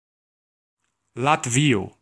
Ääntäminen
Vaihtoehtoiset kirjoitusmuodot Latvujo Synonyymit Latvujo Letonio Ääntäminen Tuntematon aksentti: IPA: /lat.ˈvi.ɔ/ Haettu sana löytyi näillä lähdekielillä: esperanto Käännös Ääninäyte Erisnimet 1.